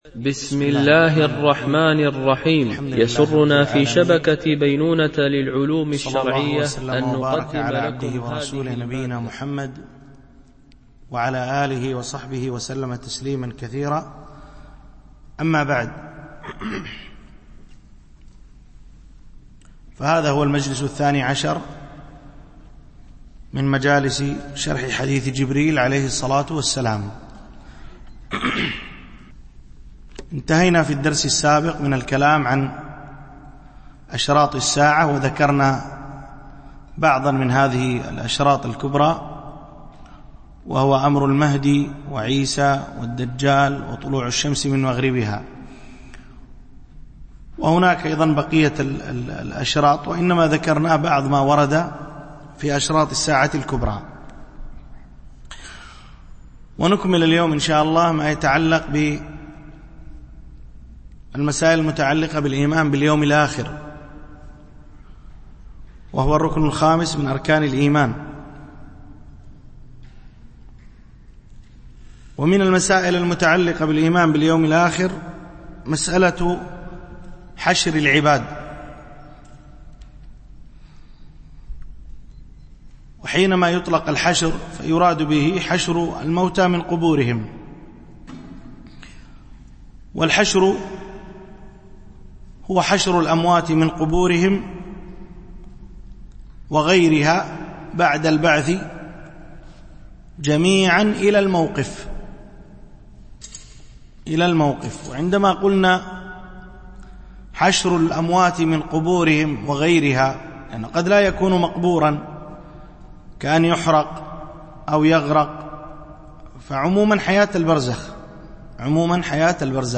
شرح حديث جبريل في بيان مراتب الدين - الدرس 12